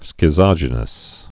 (skĭ-zŏjə-nəs, skĭt-sŏj-)